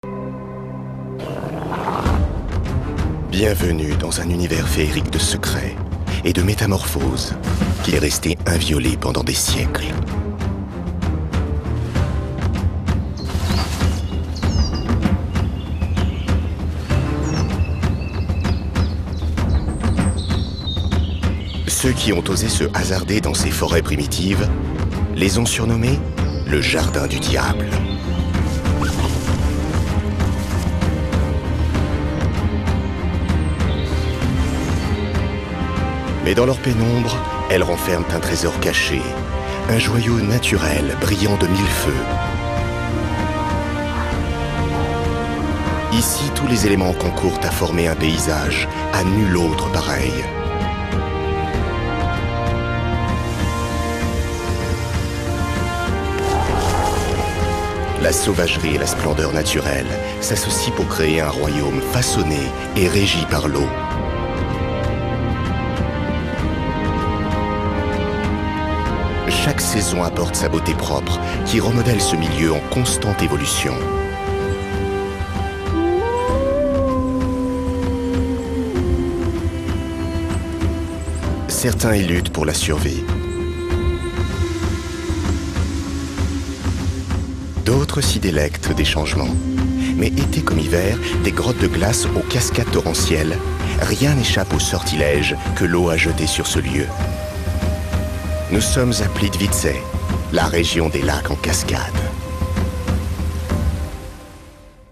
Voix-off posée, poétique et convaincante pour "Aux pays des lacs enchanteurs - Plitvice"
Voix onirique et convaincante.
Ce documentaire offre un voyage envoûtant à travers la beauté naturelle de Plitvice, un lieu d’une tranquillité presque onirique. Pour ce projet, j’ai adopté une voix grave, posée et poétique, qui se marie parfaitement avec l’ambiance du documentaire. Il m’a fallu aussi une pointe d’inquiétude et de conviction pour souligner l’urgence de la protection de ces paysages à couper le souffle.